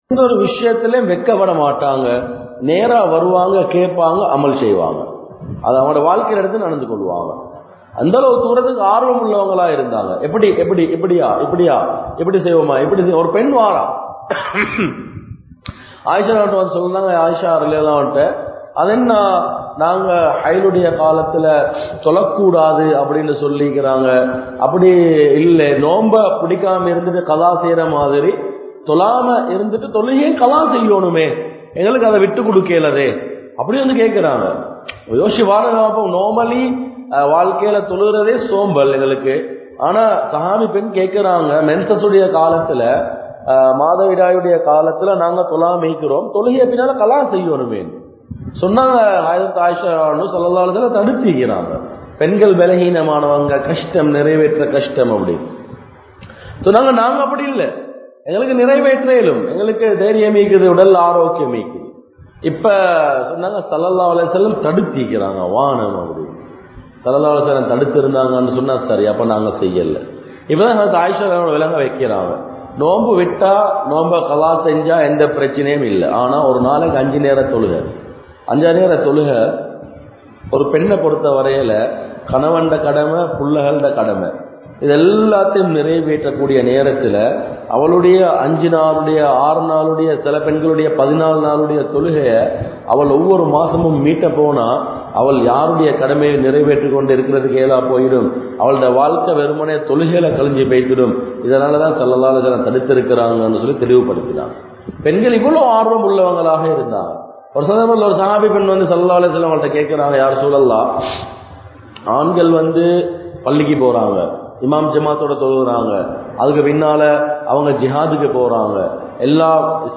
Sirantha Manaivikkuriya Panpuhal (சிறந்த மனைவிக்குரிய பண்புகள்) | Audio Bayans | All Ceylon Muslim Youth Community | Addalaichenai
Mallawapitiya Jumua Masjidh